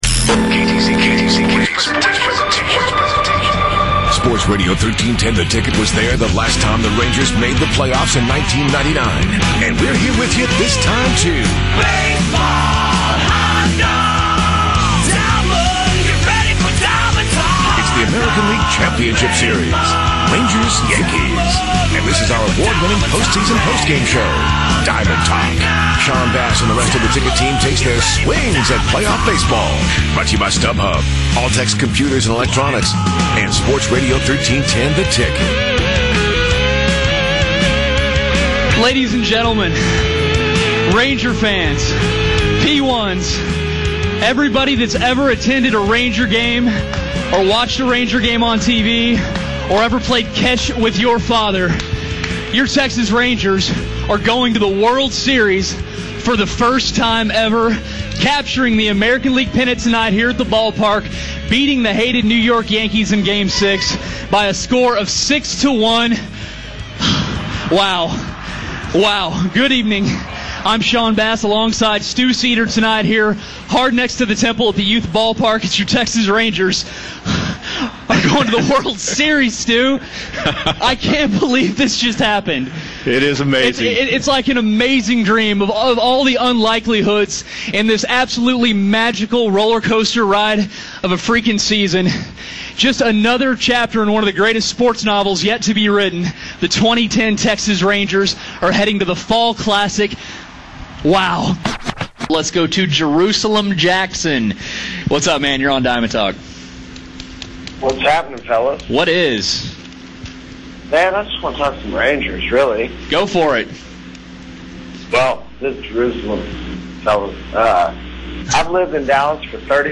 Hammered.